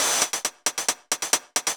Index of /musicradar/ultimate-hihat-samples/135bpm
UHH_ElectroHatC_135-05.wav